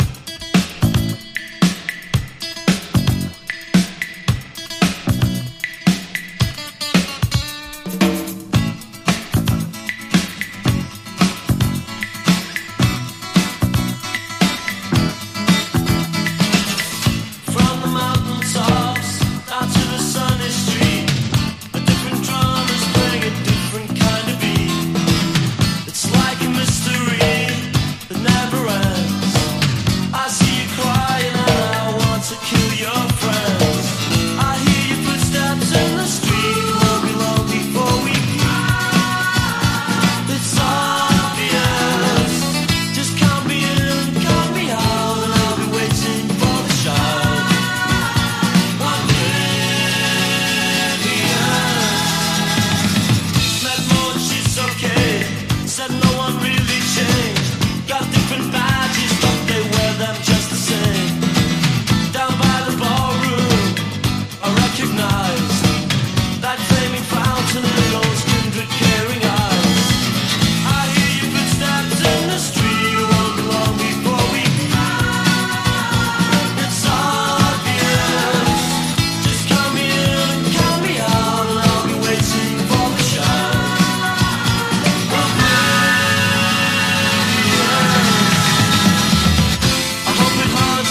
トロピカル・ネオアコな人気曲
カリビアンなギター・リフと跳ねたドラムが印象的な青春トロピカル・ネオアコ大傑作。